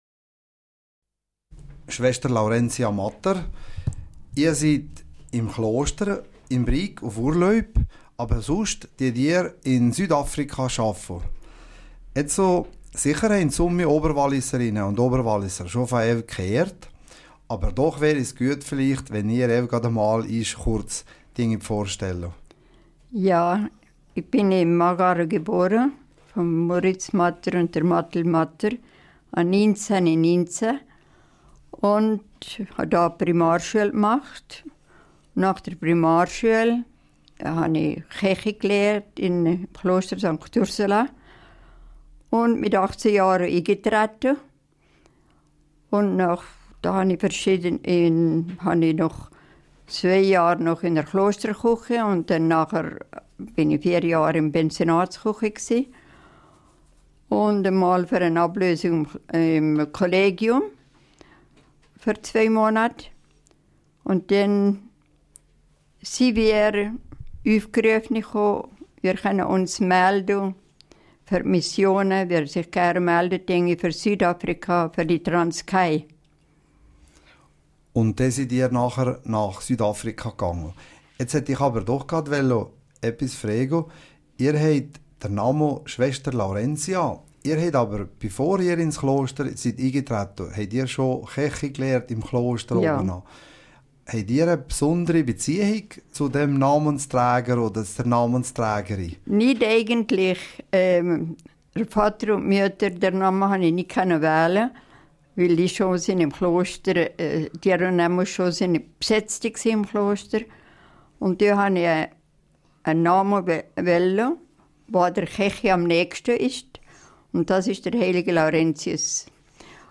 Radiosendung - émission radio